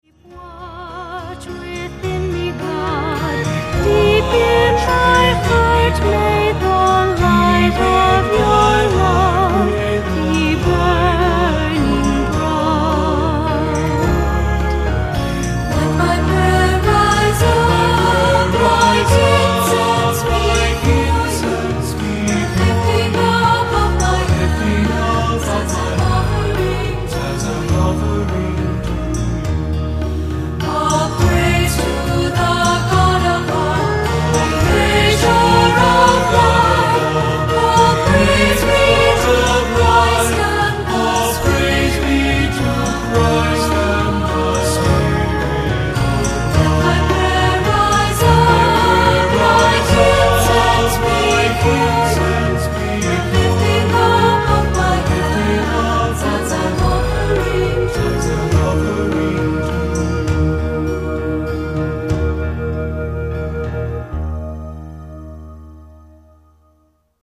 Accompaniment:      Keyboard, C Instrument
Music Category:      Christian